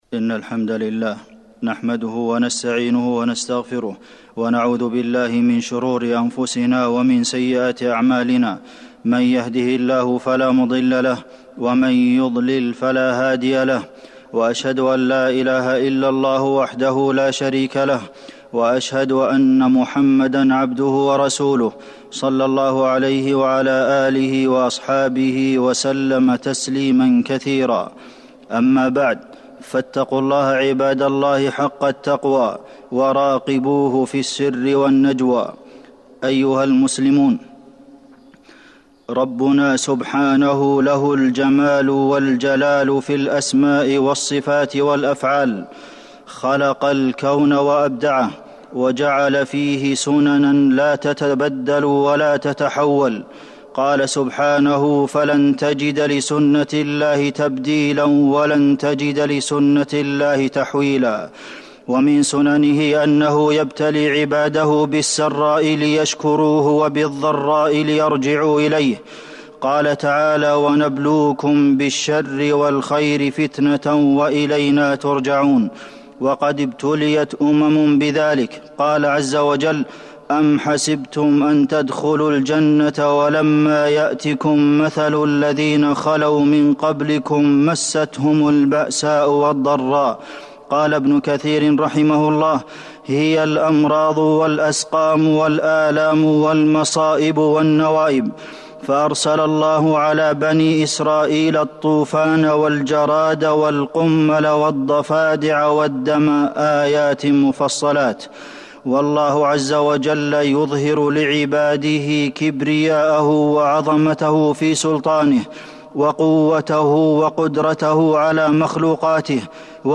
تاريخ النشر ٢٤ شعبان ١٤٤١ هـ المكان: المسجد النبوي الشيخ: فضيلة الشيخ د. عبدالمحسن بن محمد القاسم فضيلة الشيخ د. عبدالمحسن بن محمد القاسم أعمال تزيل الغمة